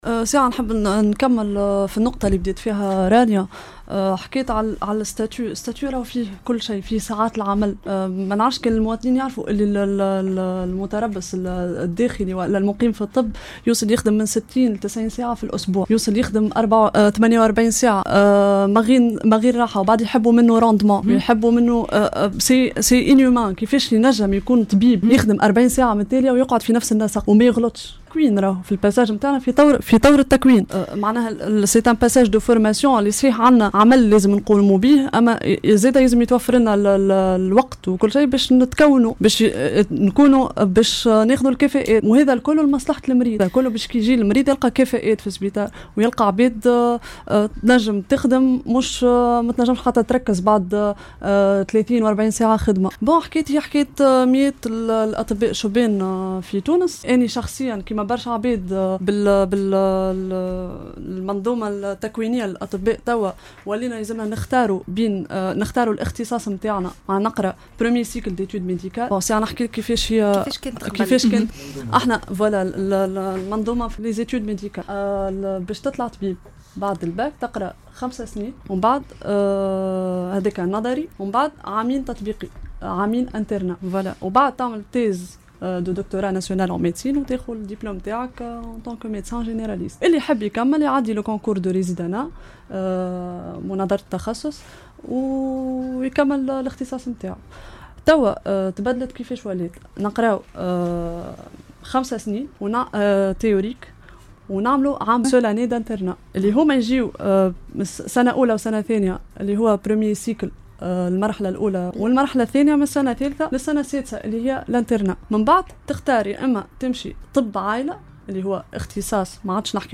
كانت ضيفة برنامج حديث الRM